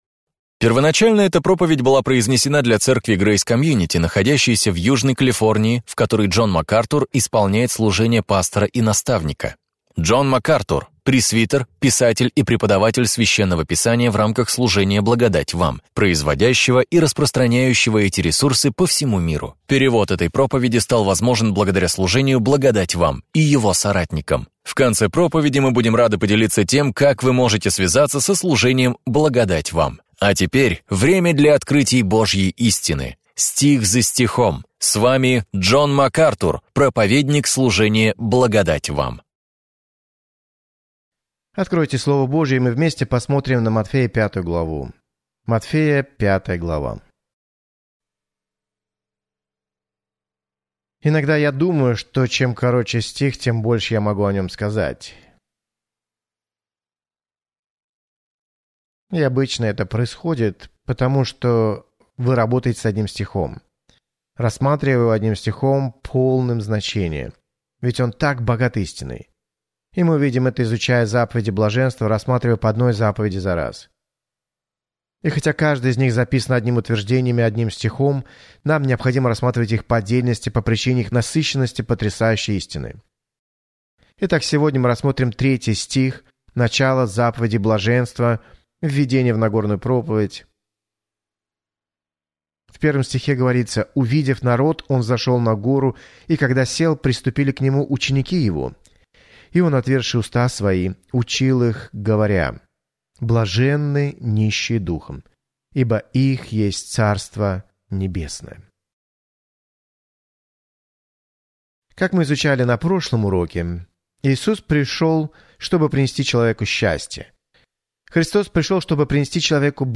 В своей проповеди «Заповеди блаженства» Джон Макартур делает обзор утверждений Христа – заповедей блаженства, исследующих моральные, этические и духовные предписания, которыми руководствуются Божьи люди.